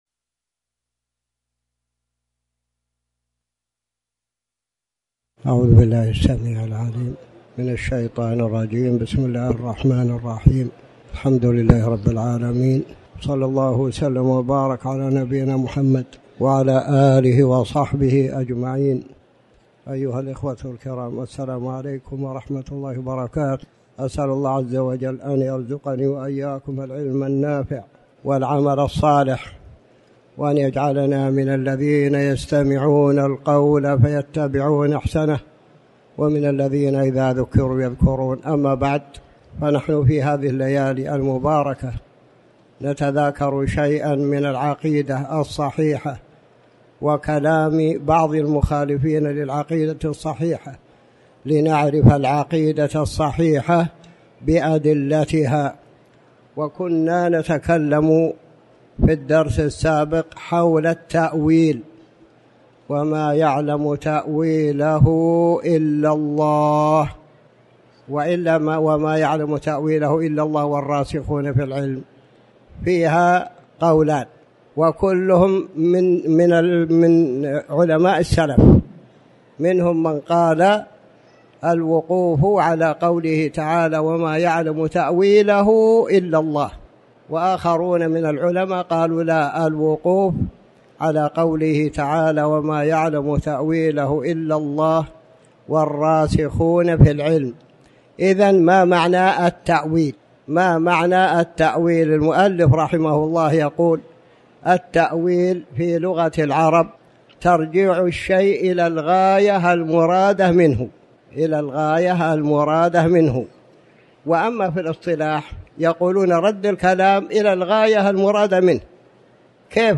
تاريخ النشر ٥ ربيع الثاني ١٤٤٠ هـ المكان: المسجد الحرام الشيخ